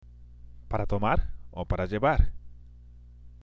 （パラ　トマール　オ　パラ　ジェバール？）